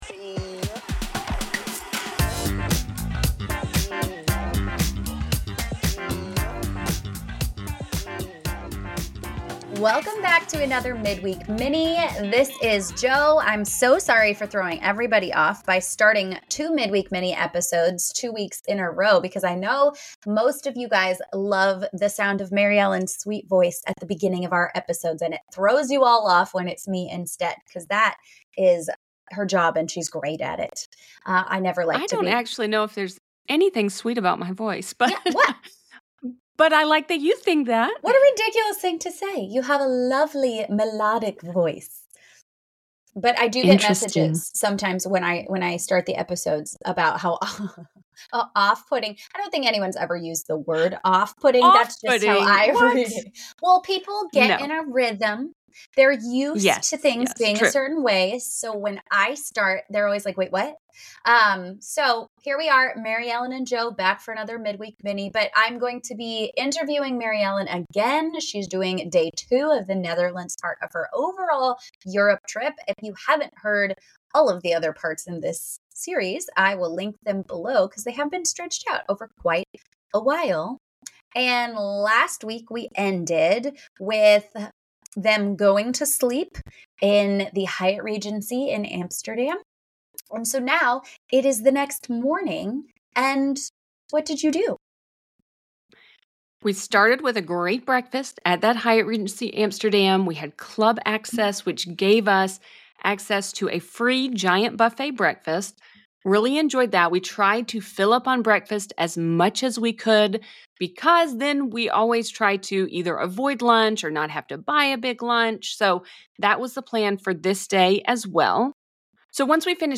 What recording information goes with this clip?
****We had MANY technical issues recording this episode. During the course of the episode you will hear some overlapping audio and also large pauses as there was a lag.